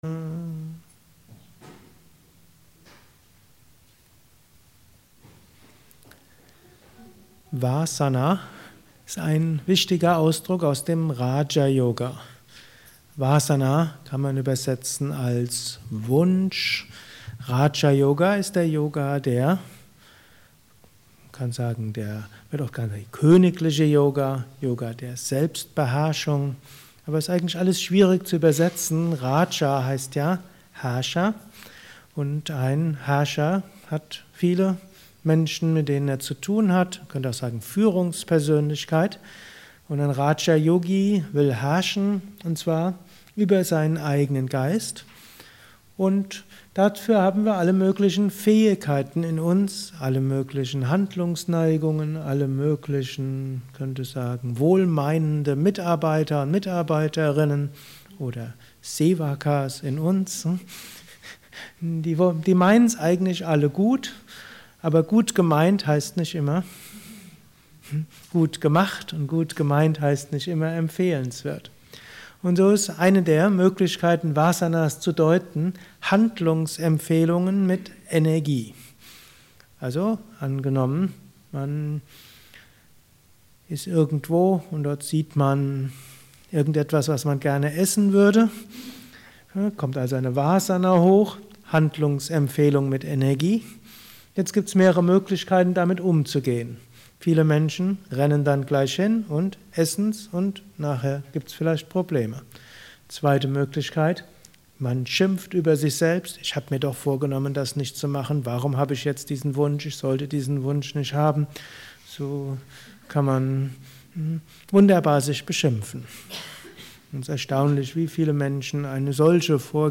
Gelesen im Anschluss nach einer Meditation im Haus Yoga Vidya Bad Meinberg.